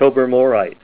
Help on Name Pronunciation: Name Pronunciation: Tobermorite + Pronunciation
Say TOBERMORITE Help on Synonym: Synonym: Crestmoreite - mixture with wilkeite